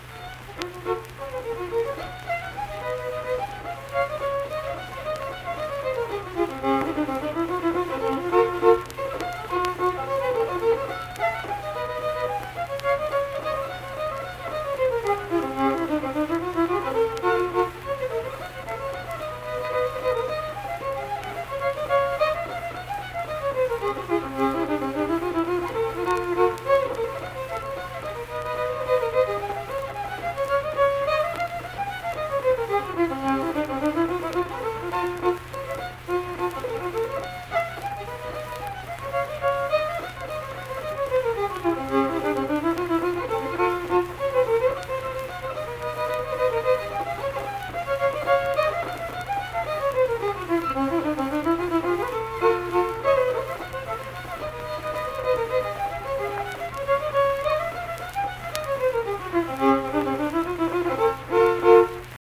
Unaccompanied fiddle music
Instrumental Music
Fiddle
Saint Marys (W. Va.), Pleasants County (W. Va.)